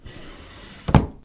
打开 关闭 " 木质抽屉 C
标签： 打开 关闭 关闭 抽屉
声道立体声